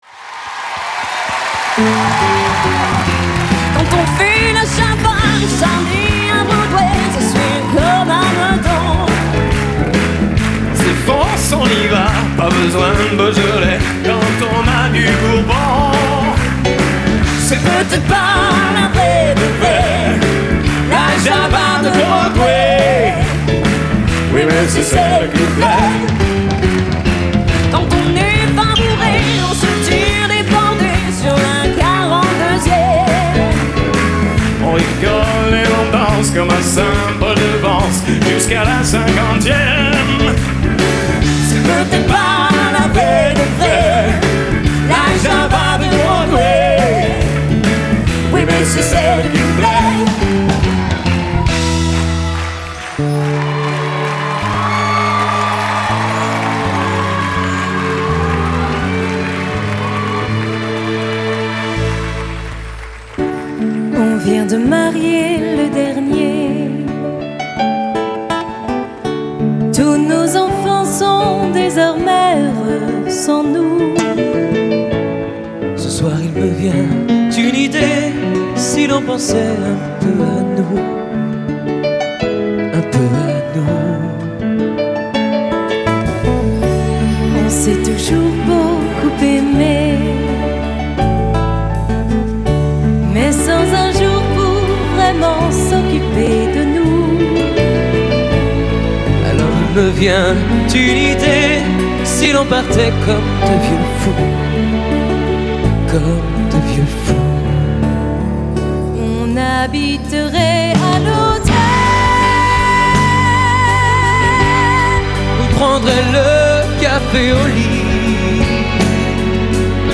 DUOS